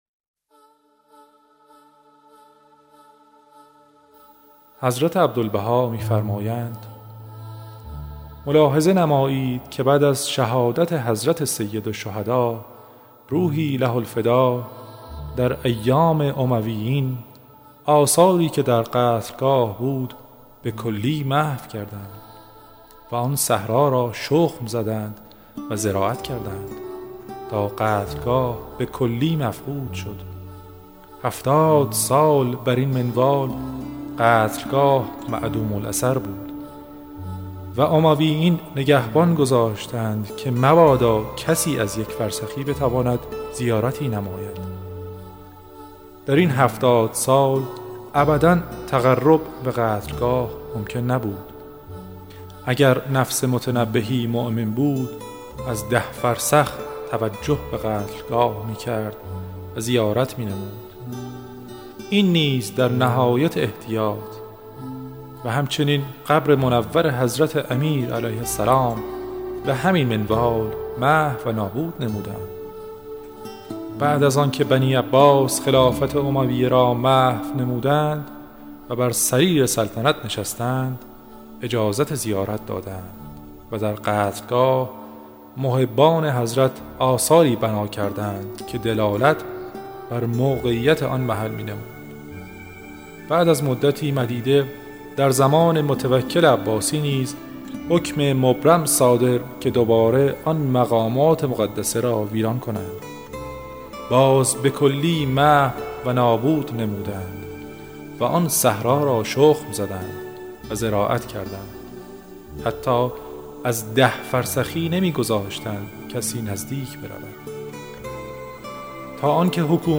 آثار مبارکه بهائی در مورد حضرت سید الشهداء به صورت صوتی همراه با موسیفی